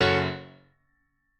admin-leaf-alice-in-misanthrope/piano34_1_022.ogg at main